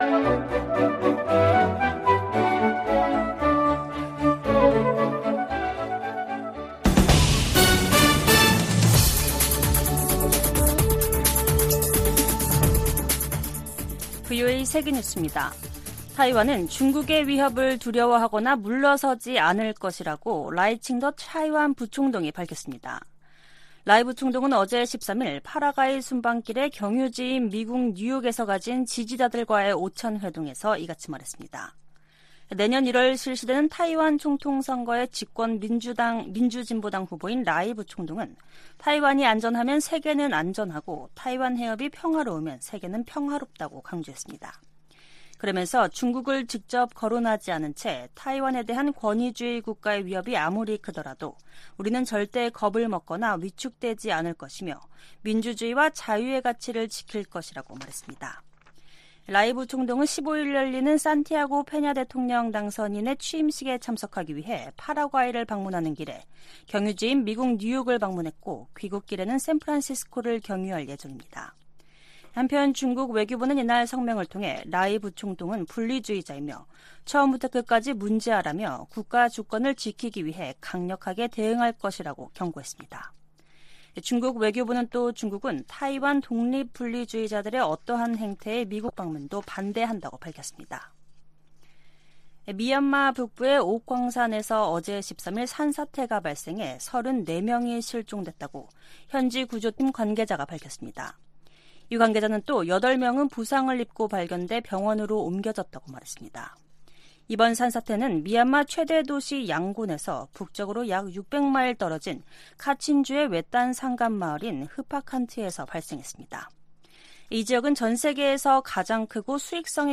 VOA 한국어 간판 뉴스 프로그램 '뉴스 투데이', 2023년 8월 14일 3부 방송입니다. 북한이 악의적 사이버 활동을 통해 안보리 제재를 회피하고 있다고 유엔 주재 미국 대표가 지적했습니다. 미 국방부는 11일 공개된 북한과 러시아 간 무기 거래 정황에 심각한 우려를 나타냈습니다. 오는 18일 열리는 미한일 정상회의에서 3국 군사훈련 정례화와 다양한 3국 간 협의체 구성 방안이 논의될 것으로 알려졌습니다.